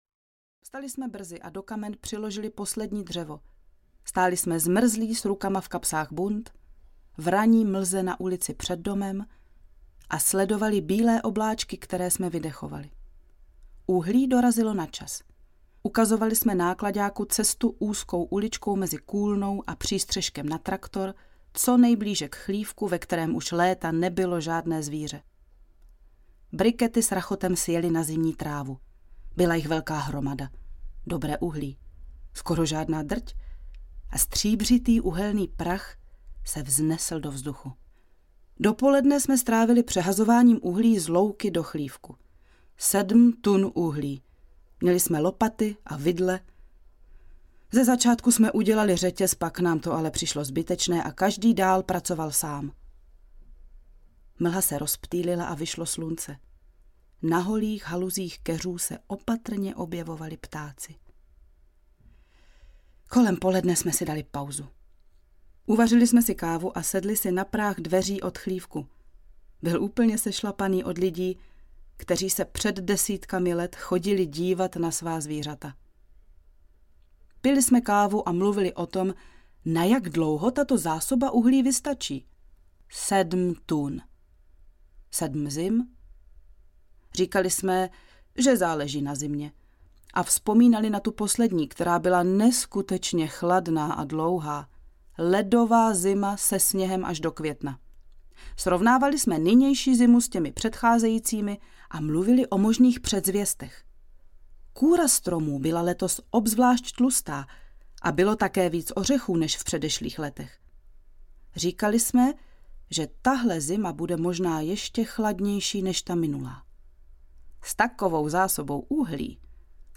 Lettipark audiokniha
Ukázka z knihy